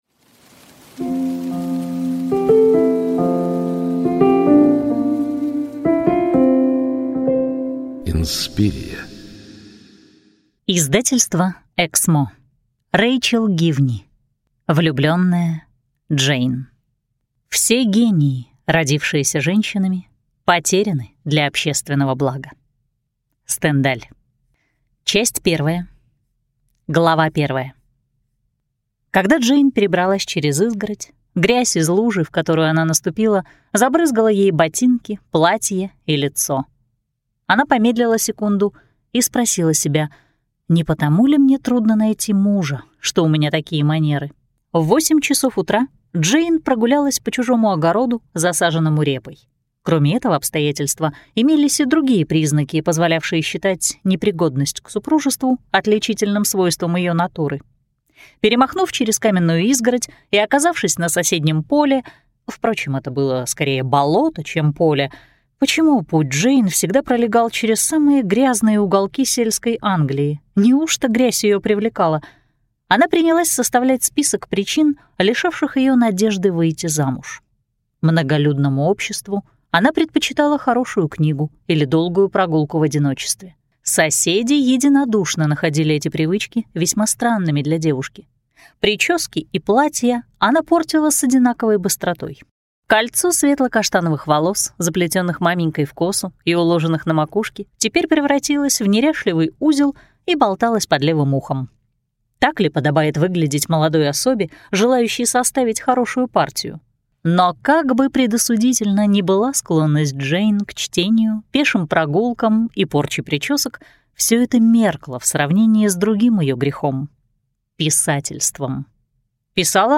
Аудиокнига Влюбленная Джейн | Библиотека аудиокниг